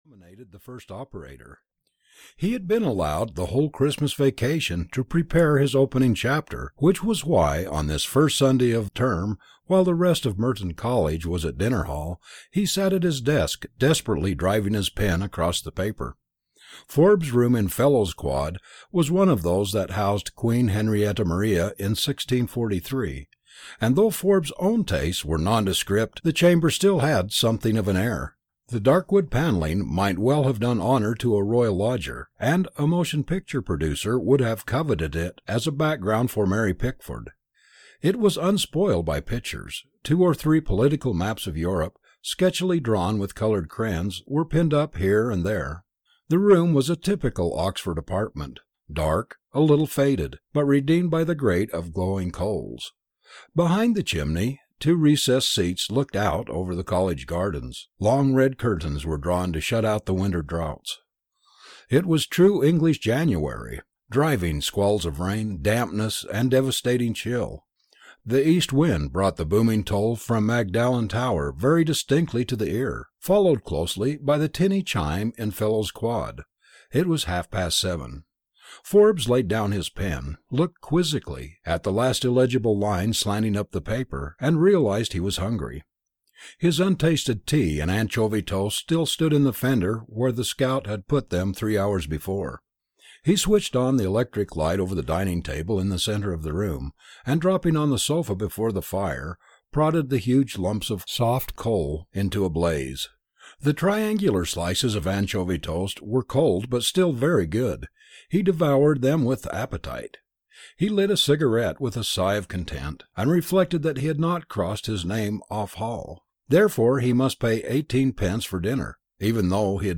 Kathleen (EN) audiokniha
Ukázka z knihy